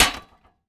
metal_hit_small_02.wav